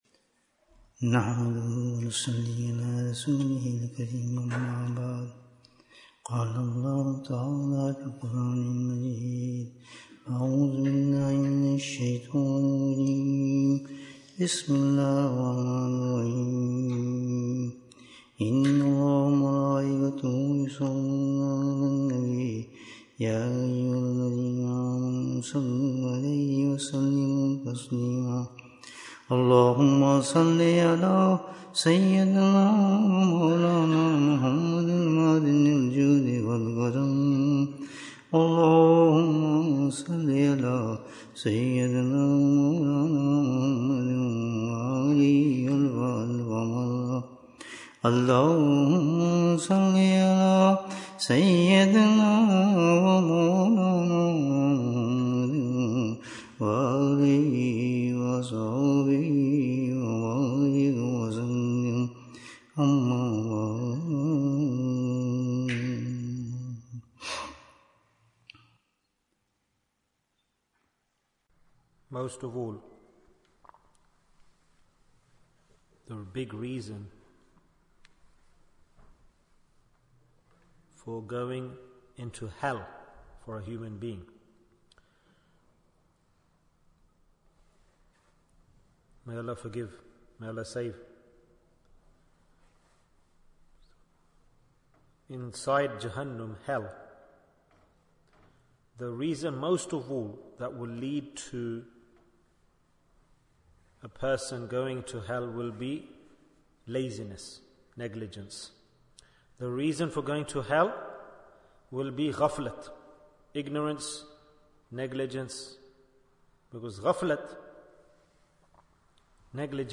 How Do We Spend the Ten Days of Dhul Hijjah? Bayan, 43 minutes6th June, 2024